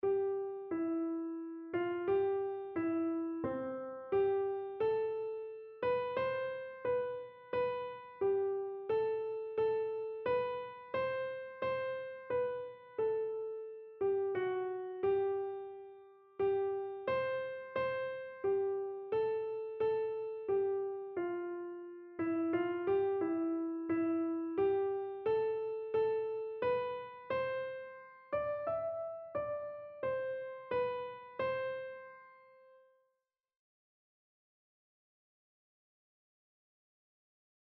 Einzelstimmen (Unisono)
• Sopran [MP3] 591 KB